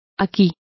Complete with pronunciation of the translation of here.